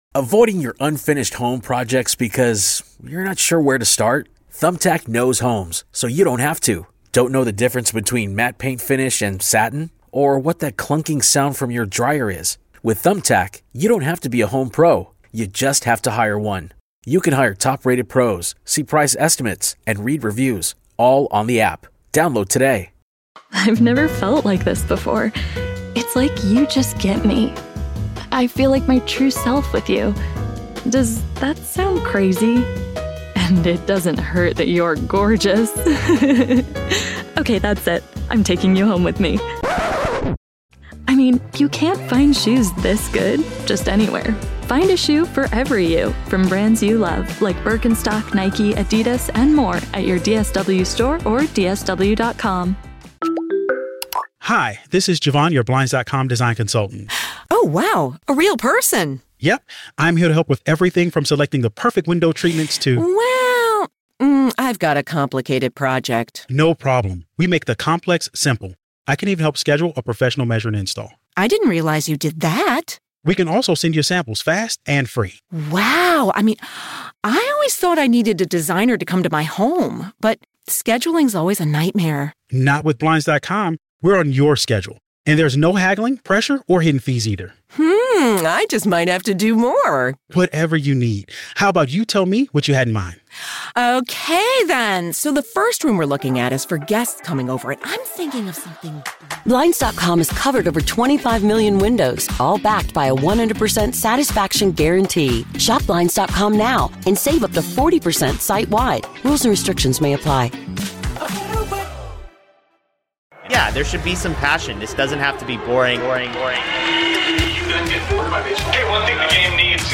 Red Sox-related audio from WEEI shows and podcasts, including postgame interviews.